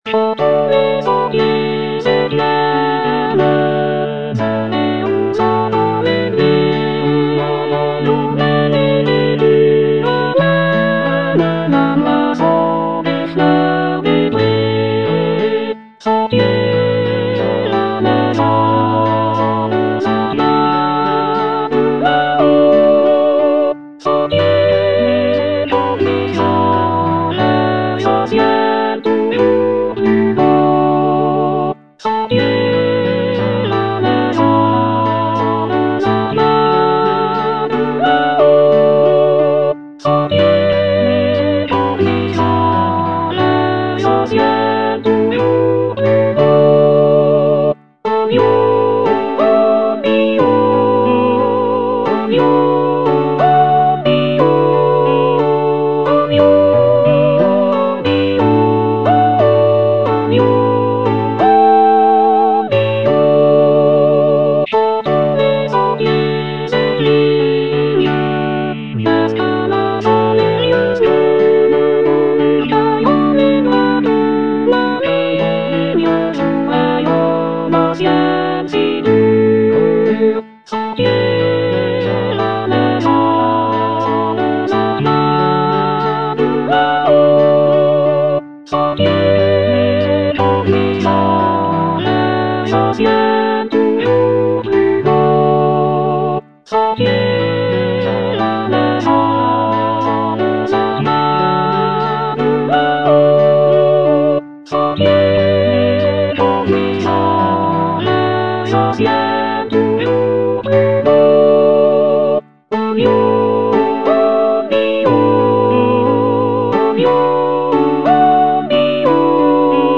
Soprano (Emphasised voice and other voices)